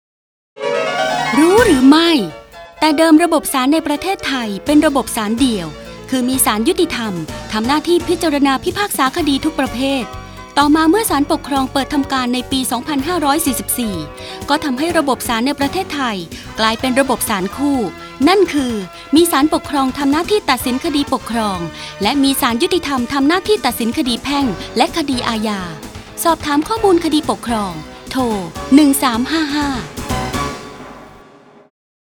สารคดีวิทยุ ชุดคดีปกครองชวนรู้ ตอนศาลคู่